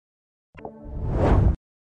SFX唰2音效下载
SFX音效